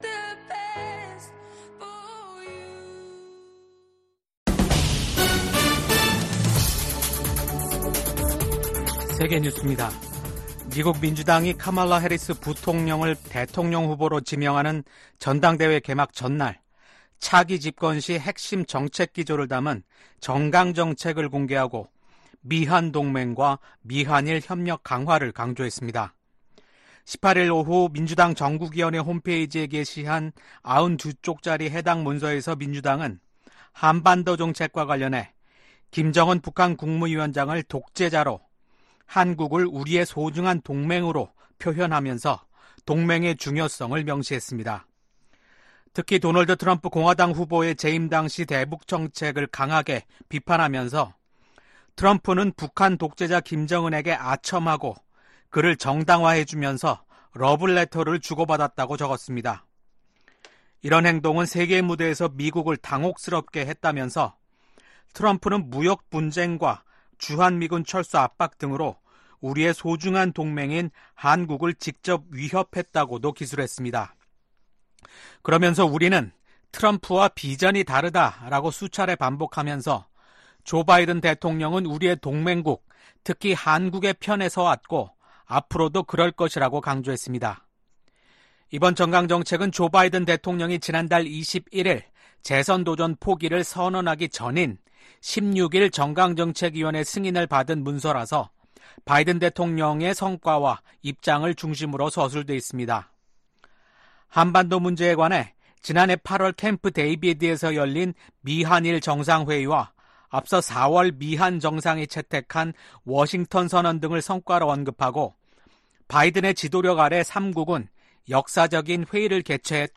VOA 한국어 아침 뉴스 프로그램 '워싱턴 뉴스 광장' 2024년 8월 20일 방송입니다. 미국과 한국, 일본이 캠프 데이비드 정상회의 1주년을 맞아 공동성명을 발표했습니다. 북한이 올해 말부터 제한적으로 외국인 관광을 재개할 것으로 알려진 가운데 미국은 자국민 방북을 절대 불허한다는 방침을 거듭 확인했습니다. 미국 정부는 윤석열 한국 대통령이 발표한 ‘8.15 통일 독트린’에 대한 지지 입장을 밝혔습니다.